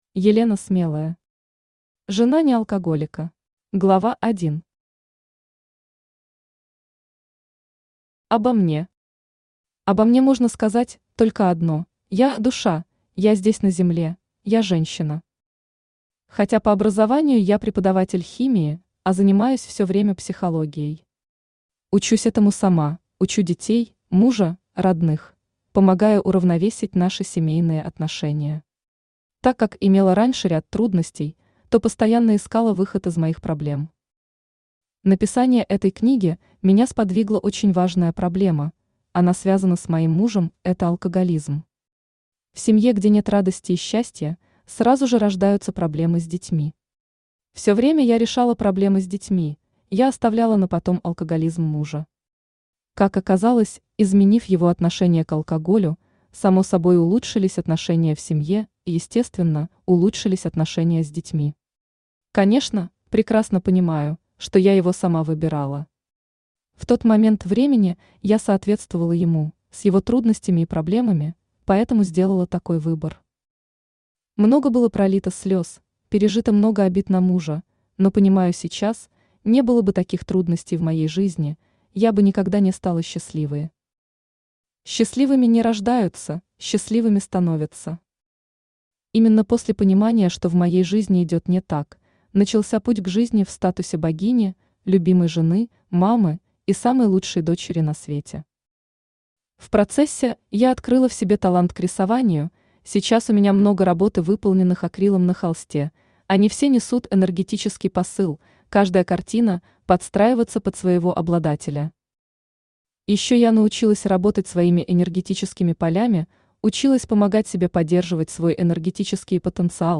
Aудиокнига Жена неАлкоголика Автор Елена Смелая Читает аудиокнигу Авточтец ЛитРес.